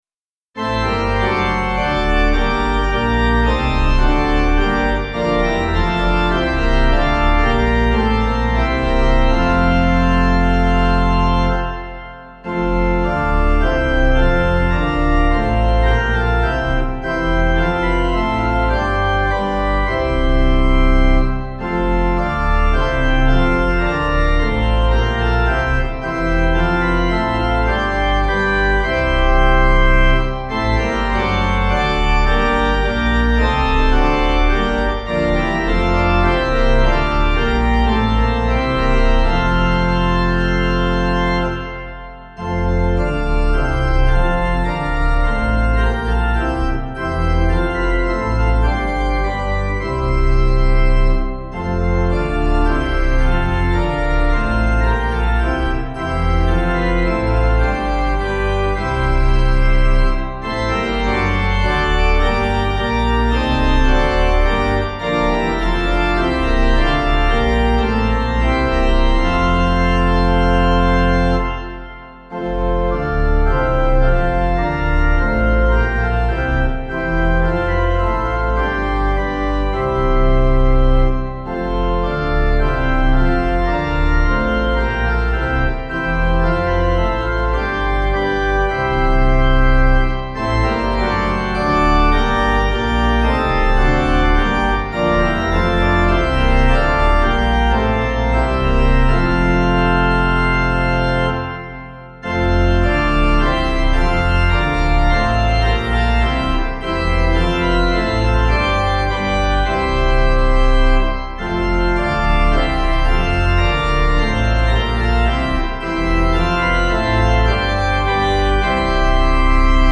Meter: 8.6.8.6.8.8
Key: F Major
Four-part harmony